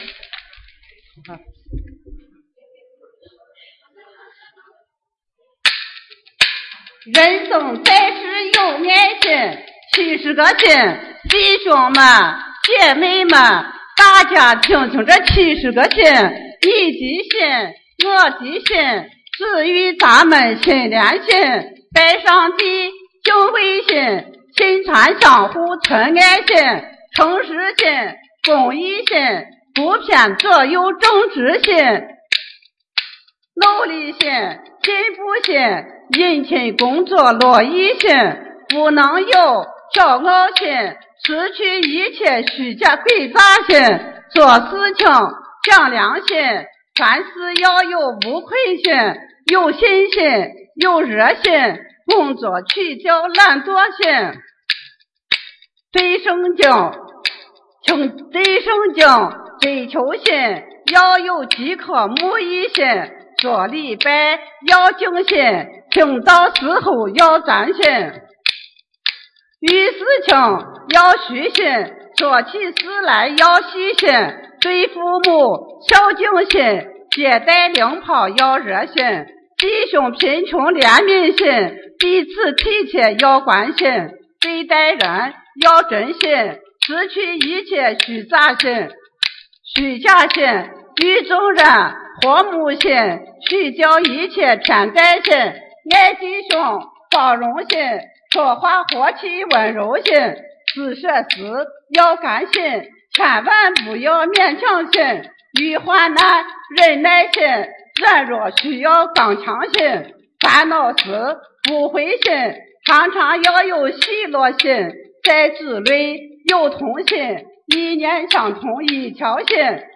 七十个心（陕西快板）(